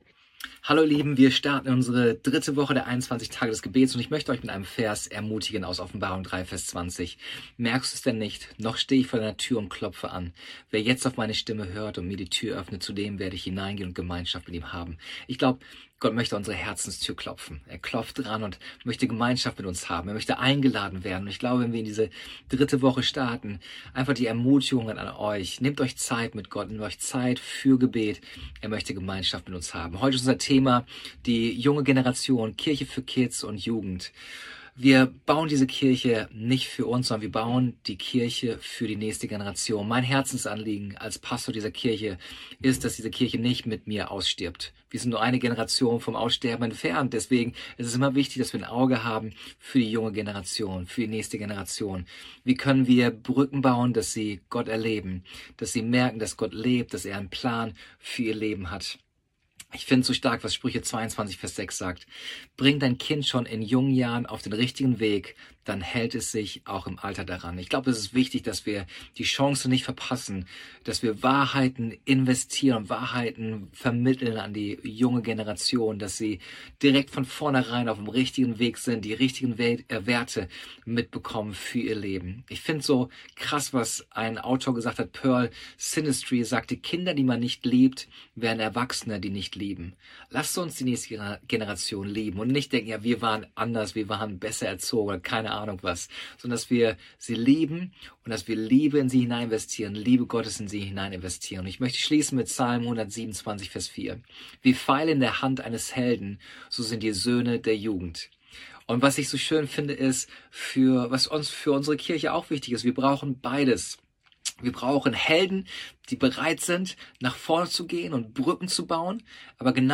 Andacht zu unseren 21 Tagen des Gebets